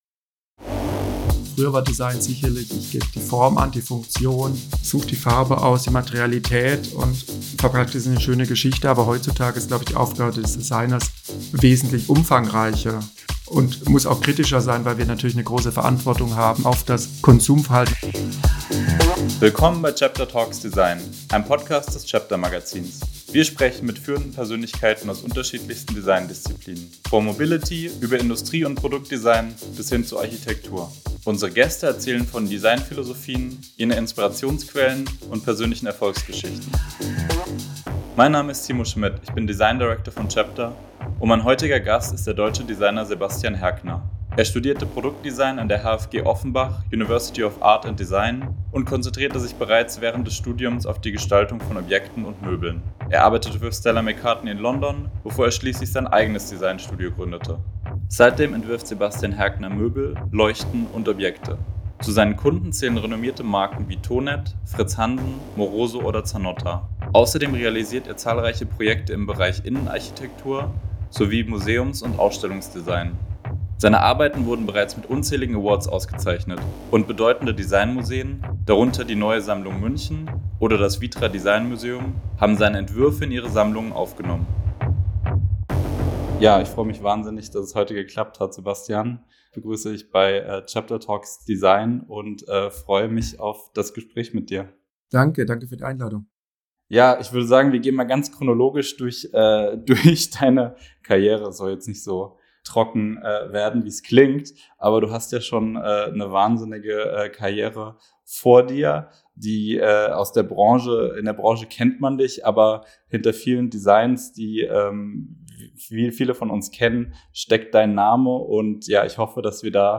Unser heutiger Gast ist der international renommierte deutsche Designer Sebastian Herkner.